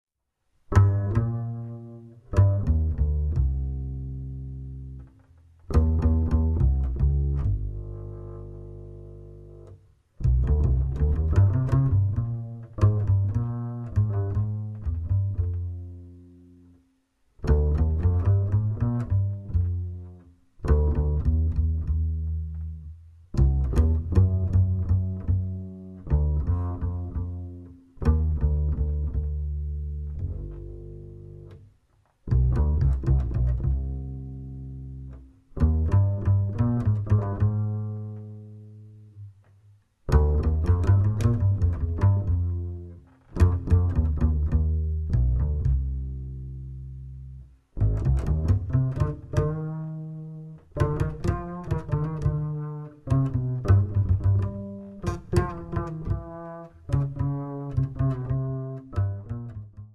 guitar
double bass